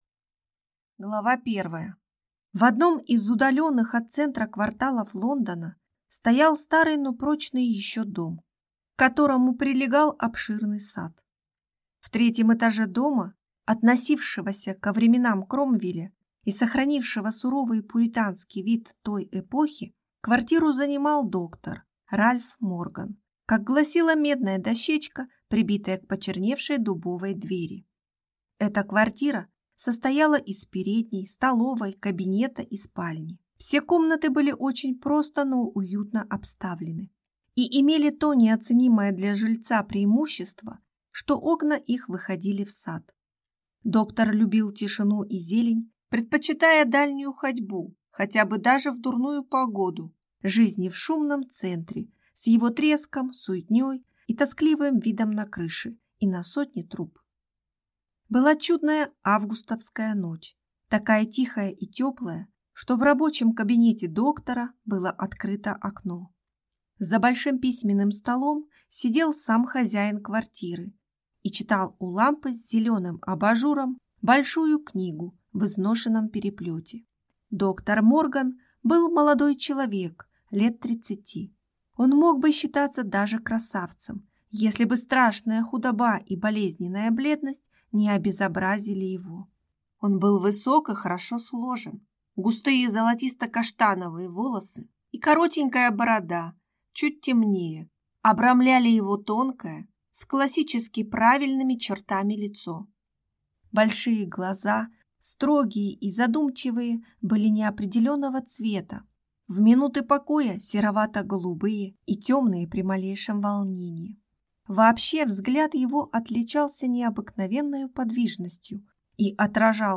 Аудиокнига Эликсир жизни | Библиотека аудиокниг